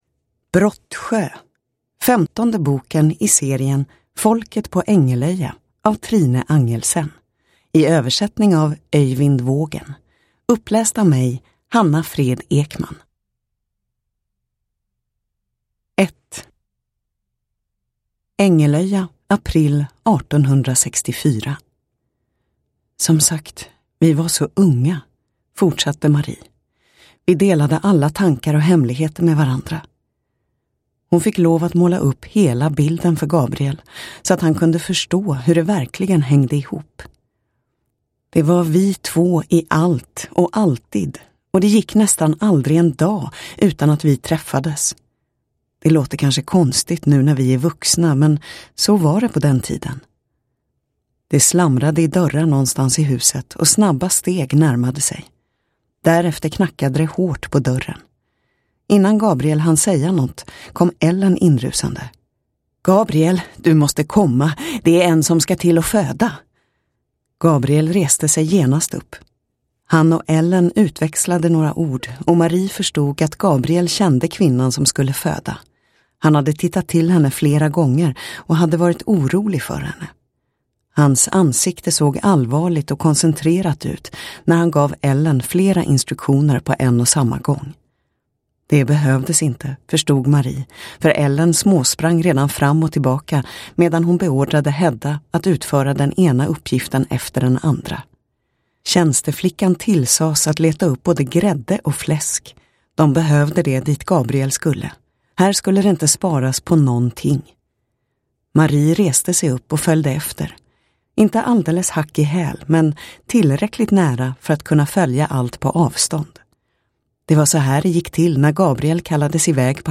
Brottsjö – Ljudbok – Laddas ner